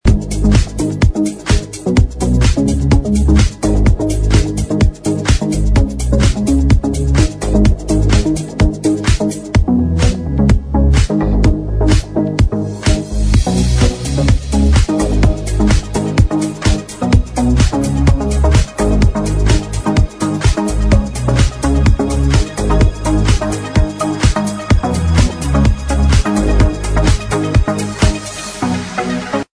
Prog Track